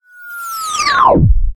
lose.ogg